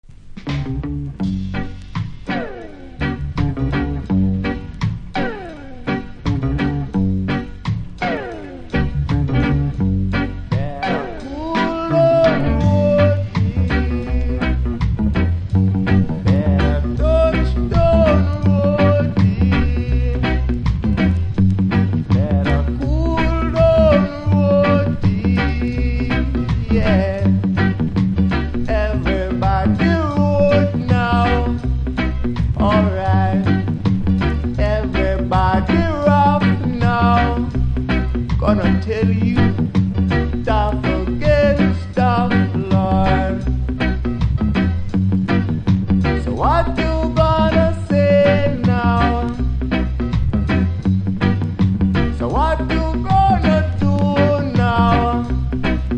曲の後半はVERSIONでショーケース・スタイルです。
多少うすキズはありますが音には影響せず良好です。